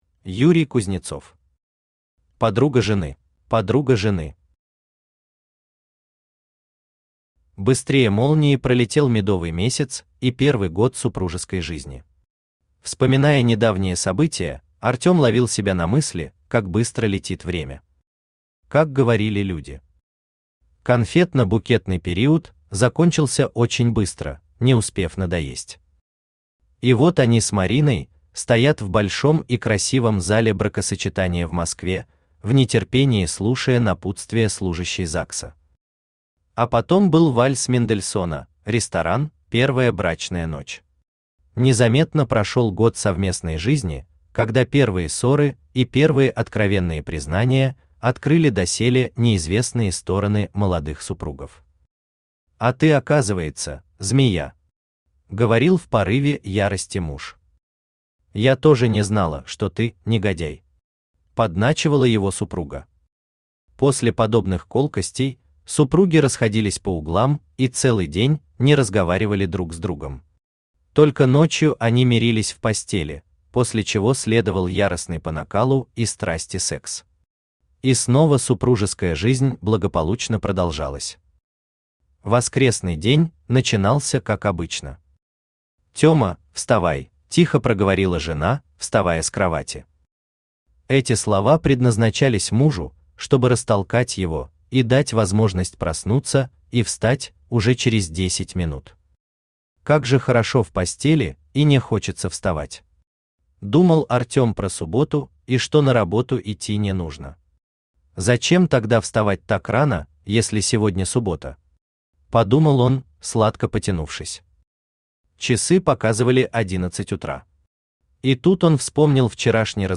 Aудиокнига Подруга жены Автор Юрий Юрьевич Кузнецов Читает аудиокнигу Авточтец ЛитРес.